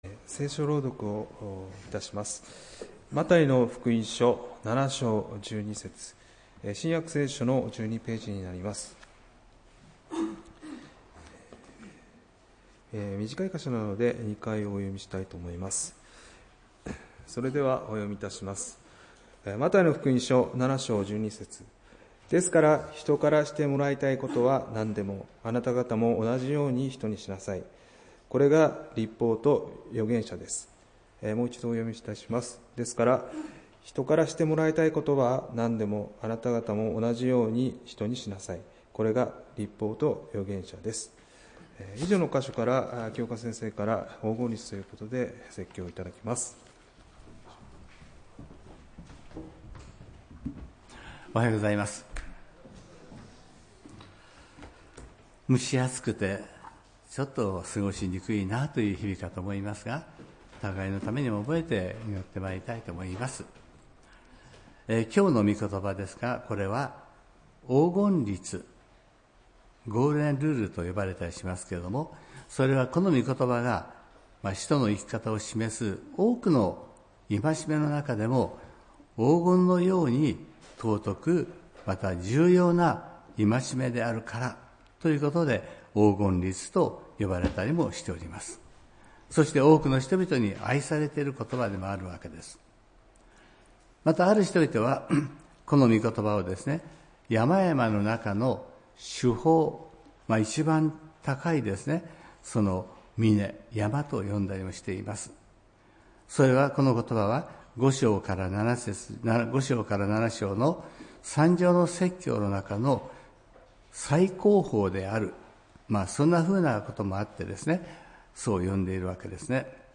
礼拝メッセージ「黄金律」(７月13日）